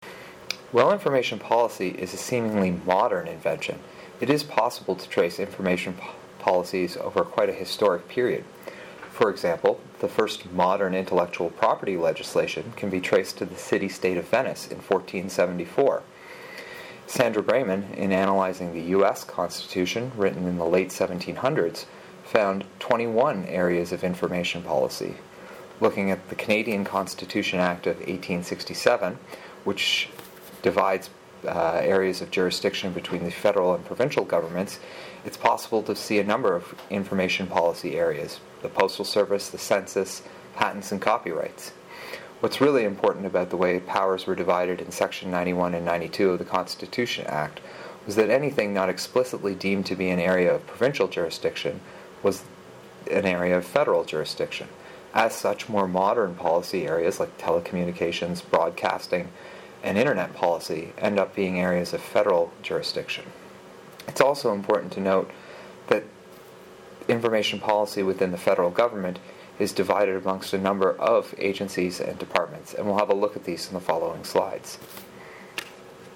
LIS 598 Information Policy - Winter 2017 - Lecture 1 - Slide 6 audio